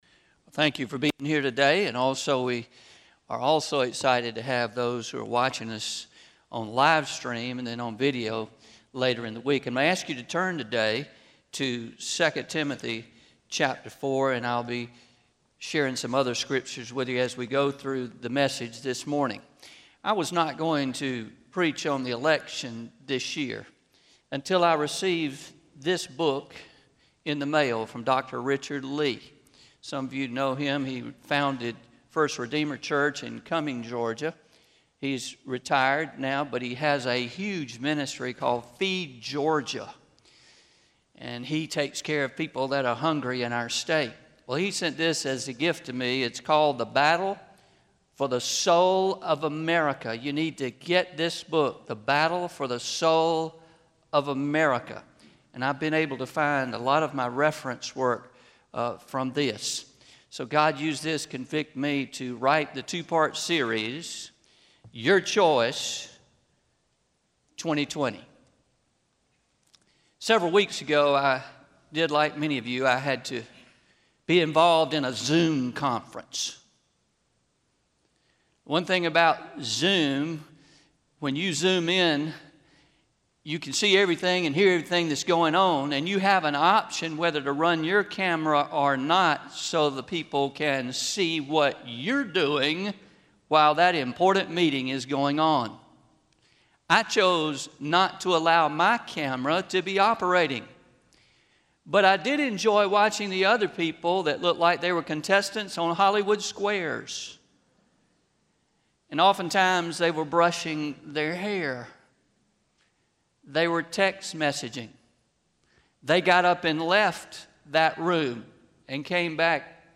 09-27-20am Sermon – Your Choice 2020 Part 2 – Traditional